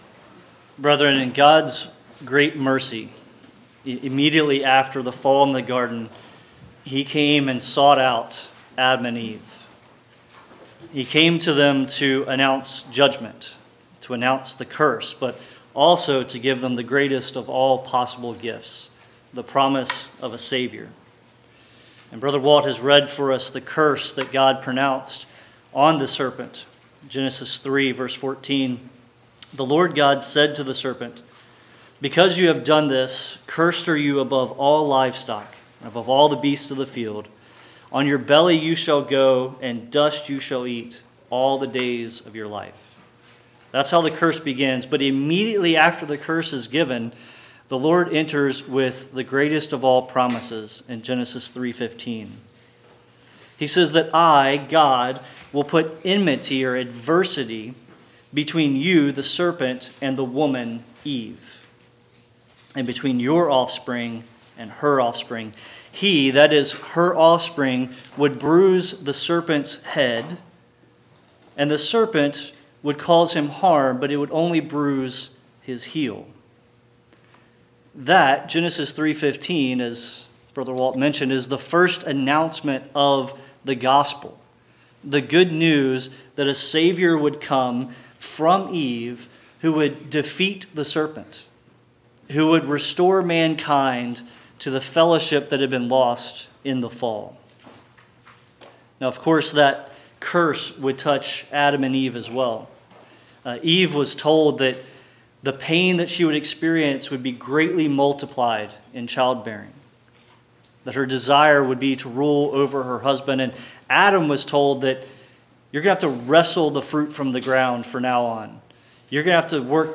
Podcast (wsbc-sermons): Play in new window | Download